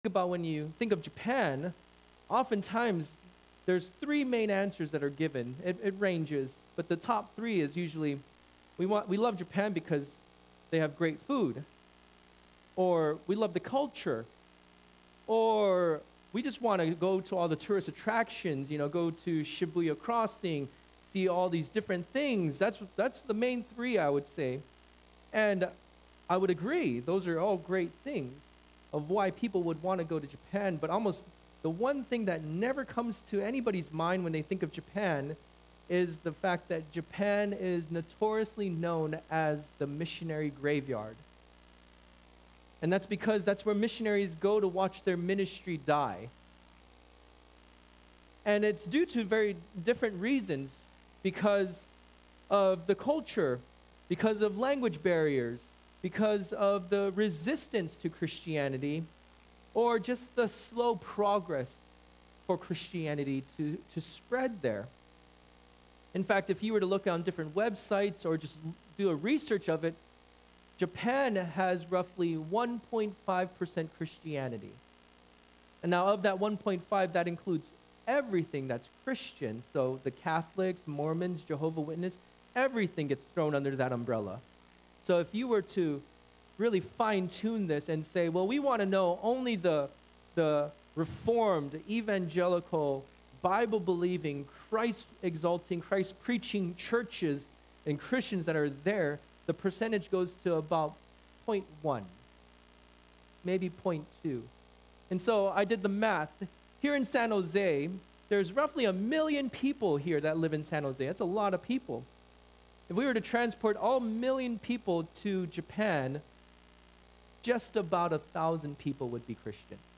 Guest Preacher | The Great Gain of Knowing Christ